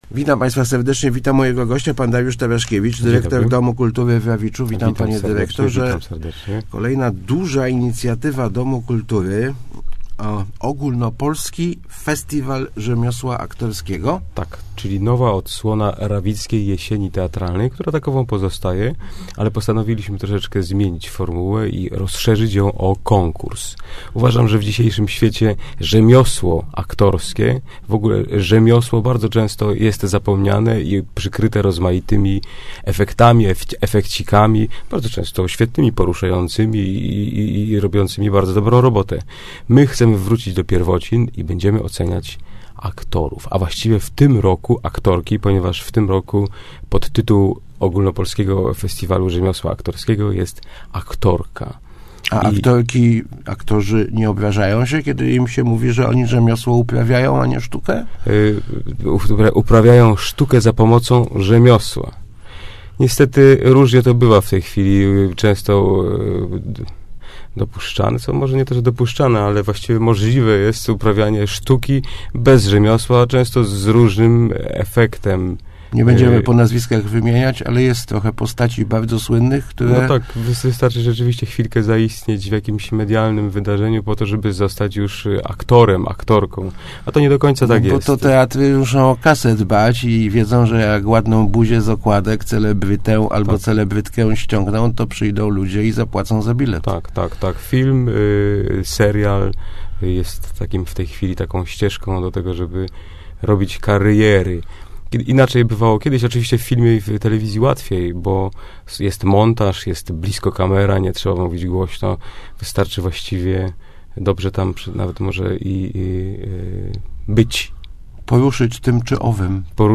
Start arrow Rozmowy Elki arrow Znane aktorki na rawickiej scenie